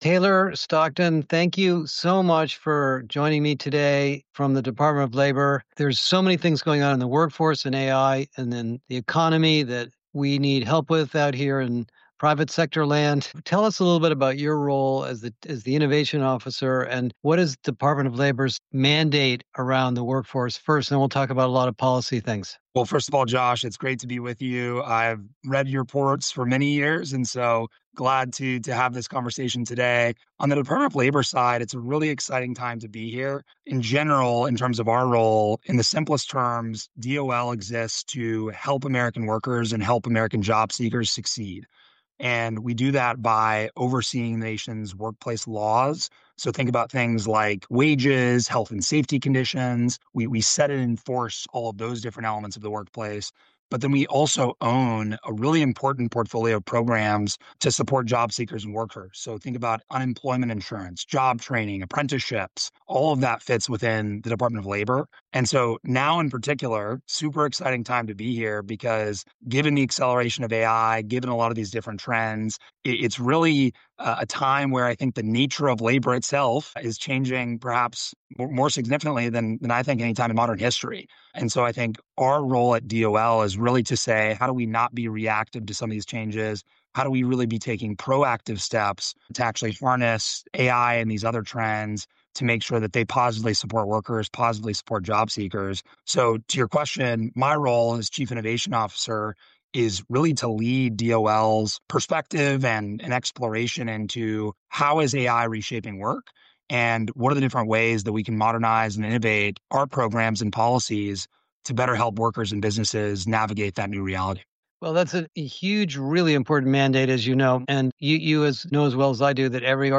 This week I’m excited to share my interview with Taylor Stockton, Chief Innovation Officer for the US Department of Labor.